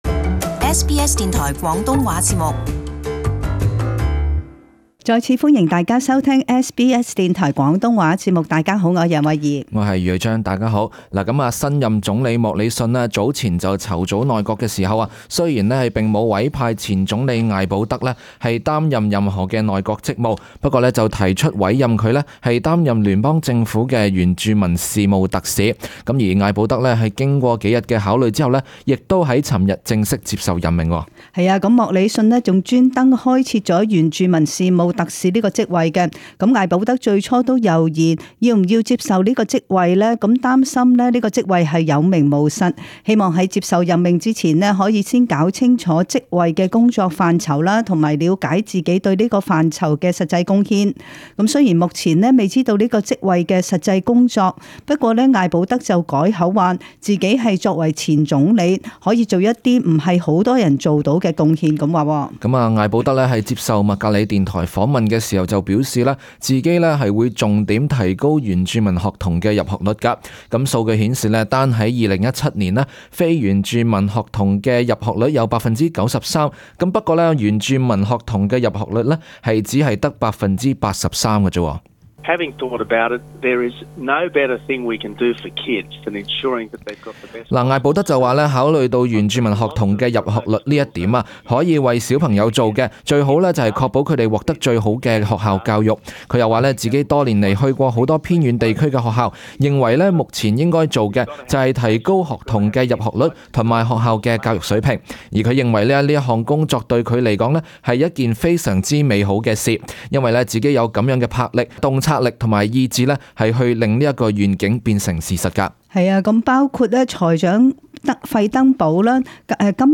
【時事報導】艾保德出任原住民事務特使